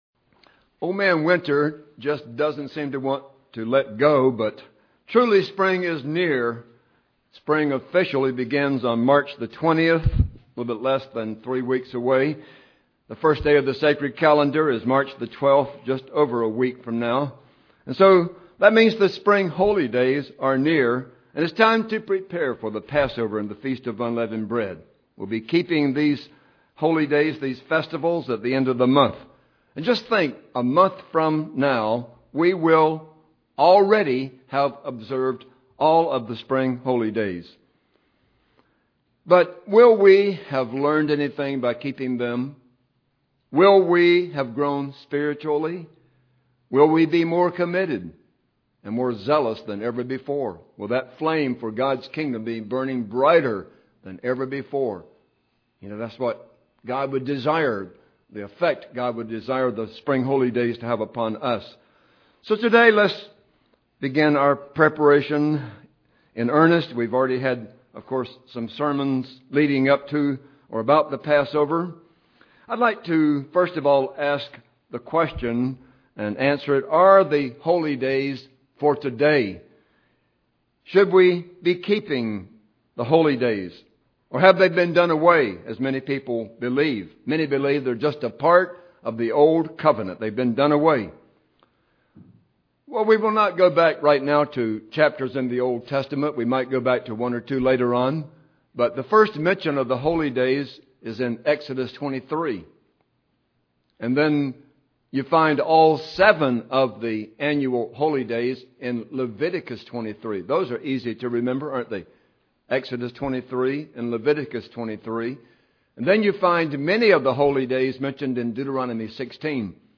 Should we observe the Biblical Holy Days or were they only for Ancient Israel? Listen to this Sermon to discover the amazing truth.